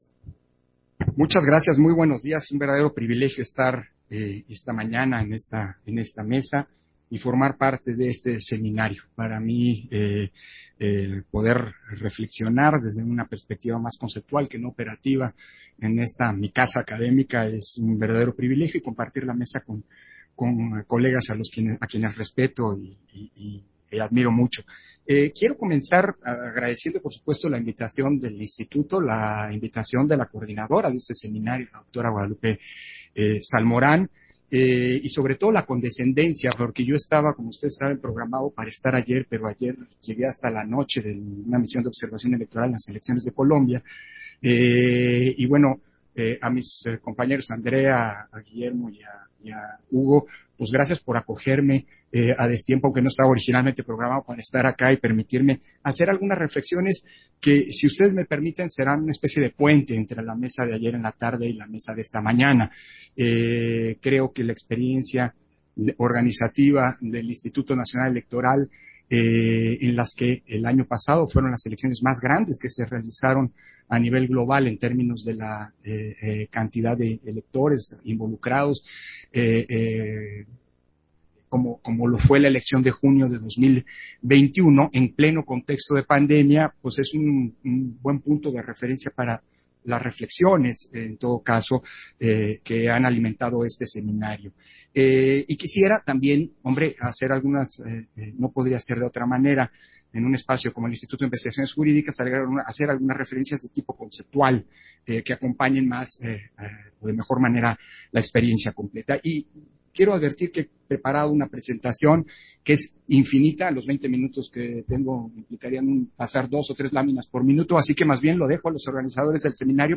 Intervención de Lorenzo Córdova, en la mesa, La pandemia ¿una emergencia global, respuestas nacionales?. Seminario Internacional de la Democracia, Estado de Derecho y Covid